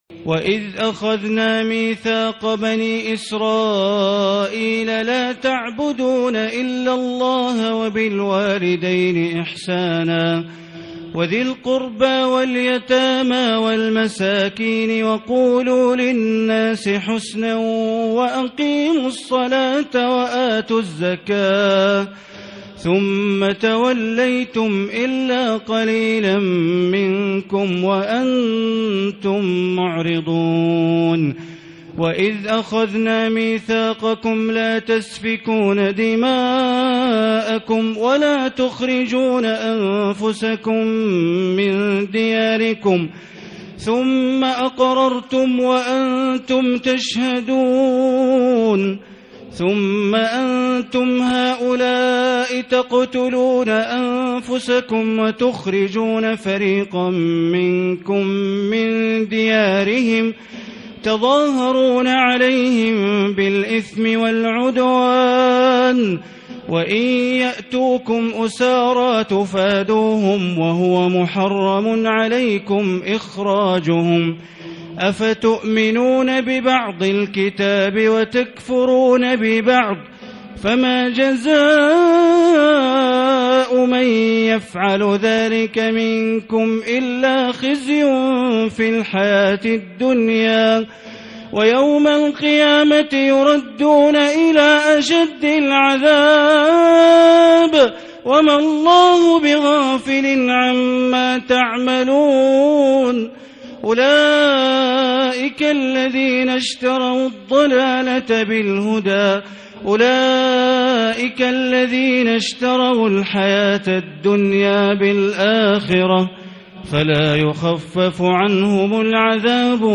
تراويح الليلة الأولى رمضان 1440هـ من سورة البقرة (83-157) Taraweeh 1st night Ramadan 1440H from Surah Al-Baqara > تراويح الحرم المكي عام 1440 🕋 > التراويح - تلاوات الحرمين